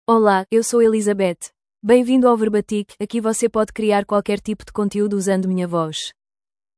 ElizabethFemale Portuguese AI voice
Elizabeth is a female AI voice for Portuguese (Portugal).
Voice sample
Female
Elizabeth delivers clear pronunciation with authentic Portugal Portuguese intonation, making your content sound professionally produced.